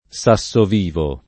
vai all'elenco alfabetico delle voci ingrandisci il carattere 100% rimpicciolisci il carattere stampa invia tramite posta elettronica codividi su Facebook Sassovivo [ S a SS ov & vo ] (raro Sasso Vivo [ id. ]) top. (Umbria)